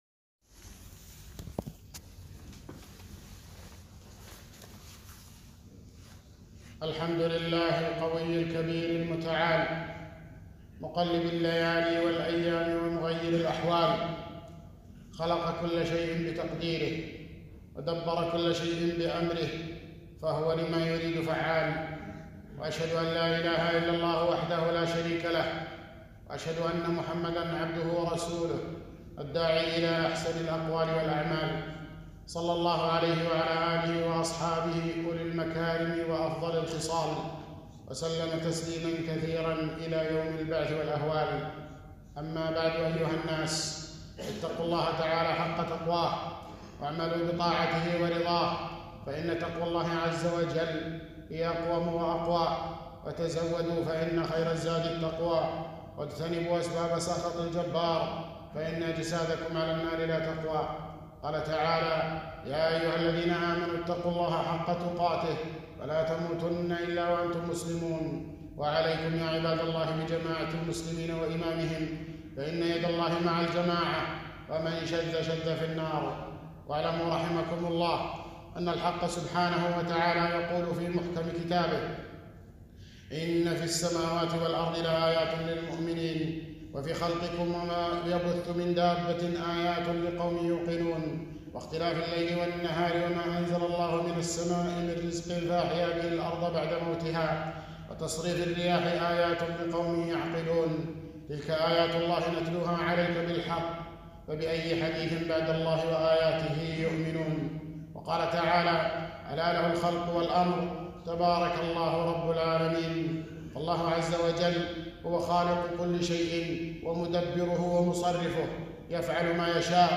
خطبة - الاعتبار بالريح الشديدة والغبار